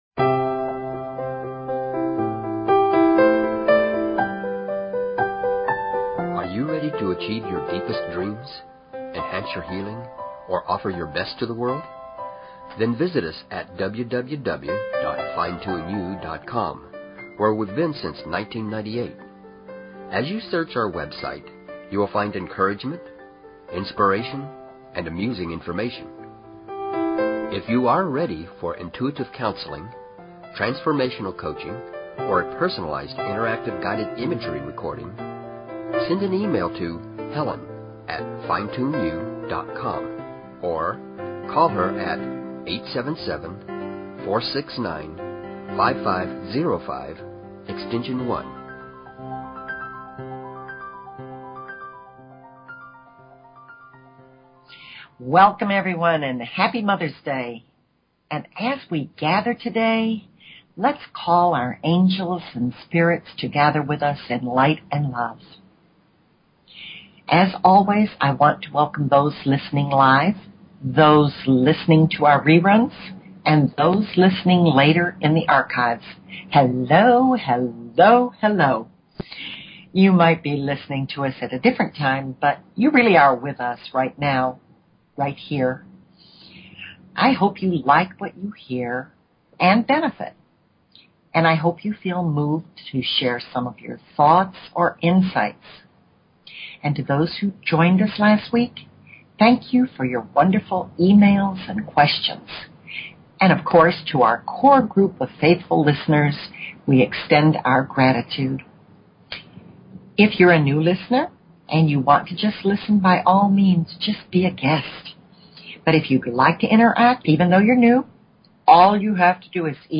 Talk Show Episode, Audio Podcast, Fine_Tune_You and Courtesy of BBS Radio on , show guests , about , categorized as